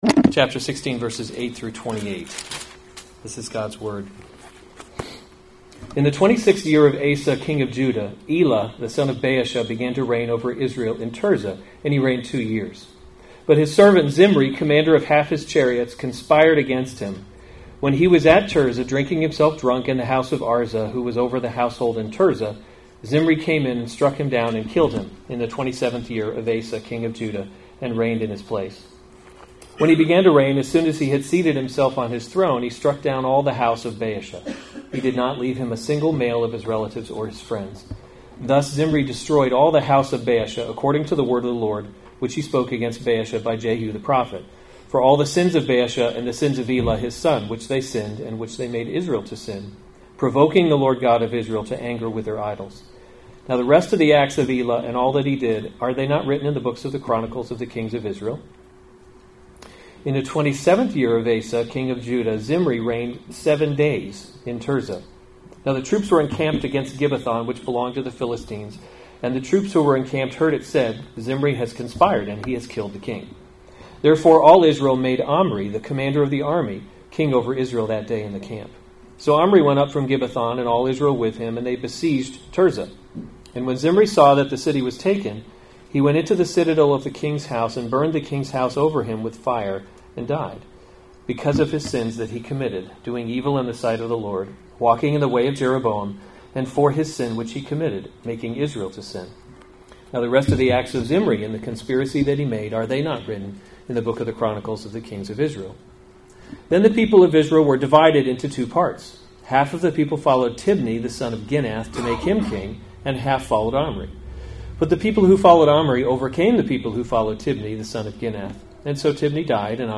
April 6, 2019 1 Kings – Leadership in a Broken World series Weekly Sunday Service Save/Download this sermon 1 Kings 16:8-28 Other sermons from 1 Kings Elah Reigns in Israel […]